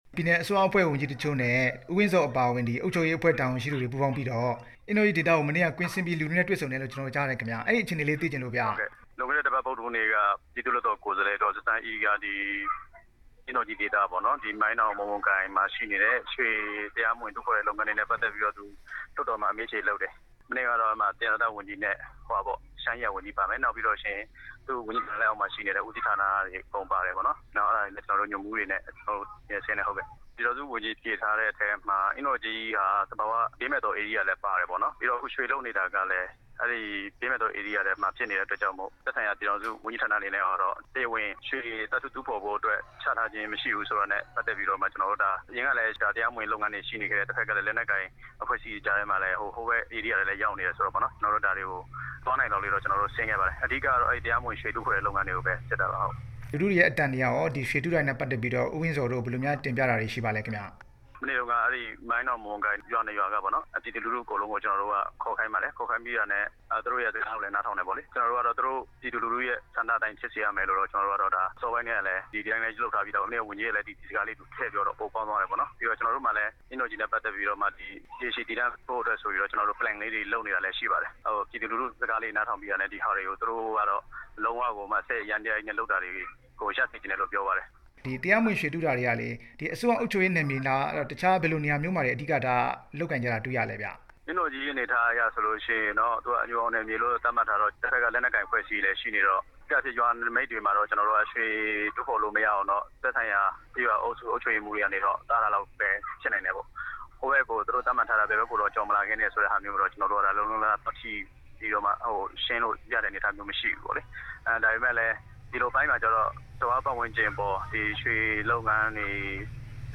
အင်းတော်ကြီးဒေသ ရွှေတူးဖော်မှုအကြောင်း မေးမြန်းချက်